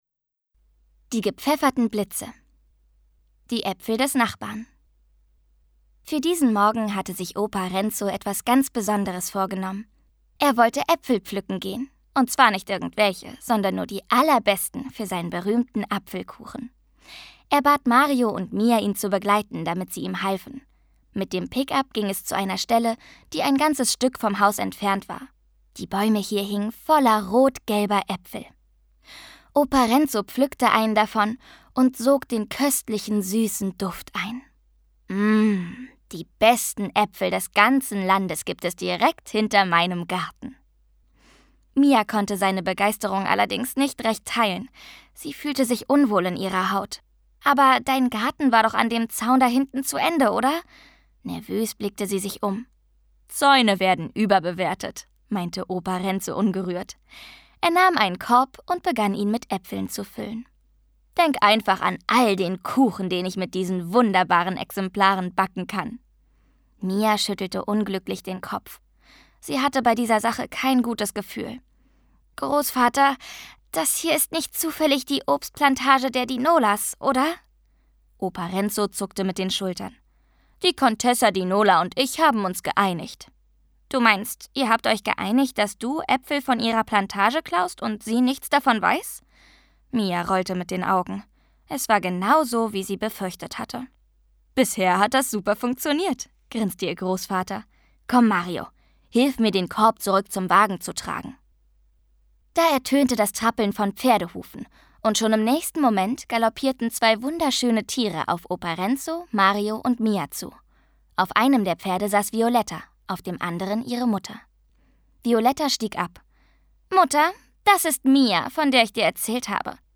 Themenwelt Kinder- / Jugendbuch Erstlesealter / Vorschulalter